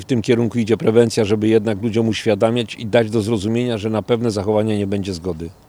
Sleza-4-burmistrz.mp3